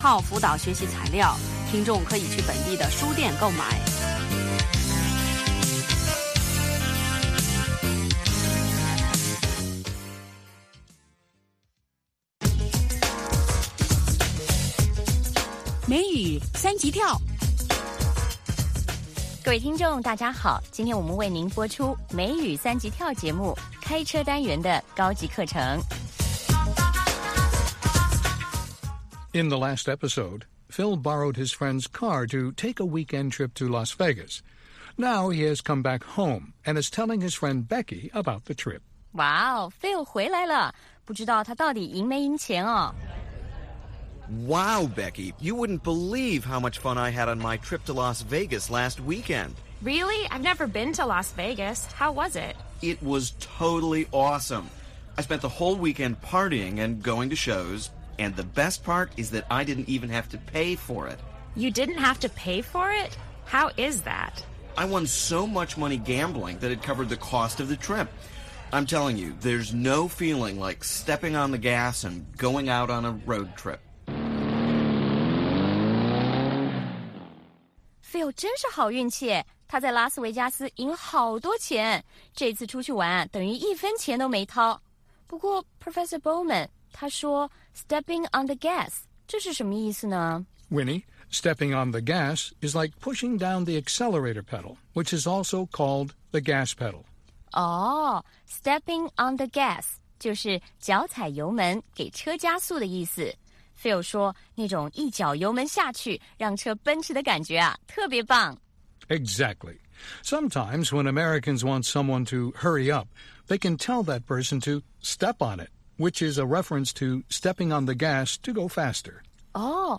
北京时间下午5-6点广播节目。广播内容包括美语训练班(学个词， 美国习惯用语，美语怎么说，英语三级跳， 礼节美语以及体育美语)，以及《时事大家谈》(重播)